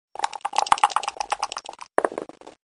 dice.ogg